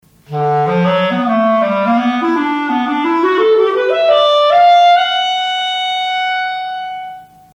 Klarinette
Sie kann singen, jauchzen, klagen, jaulen, dudeln, schnarren, zetern, kichern, swingen, hauchen… Keines unter den Blasinstrumenten kommt der Ausdrucksskala der menschlichen Stimme näher.
Klarinette.mp3